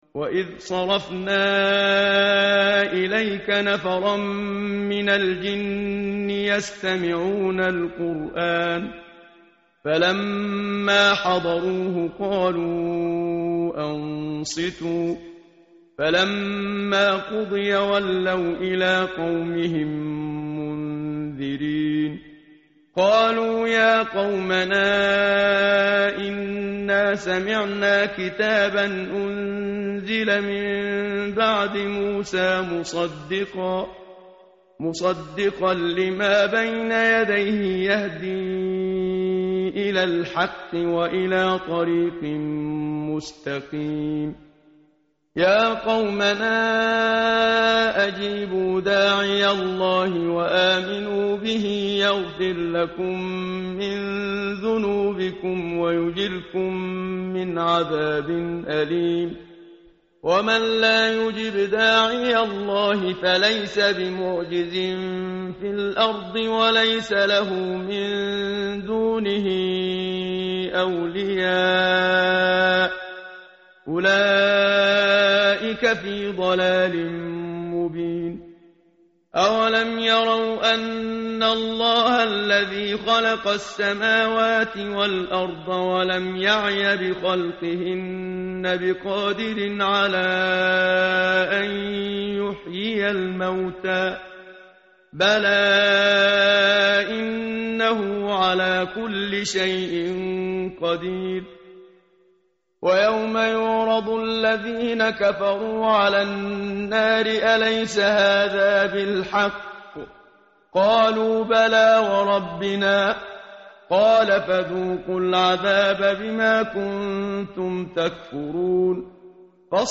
متن قرآن همراه باتلاوت قرآن و ترجمه
tartil_menshavi_page_506.mp3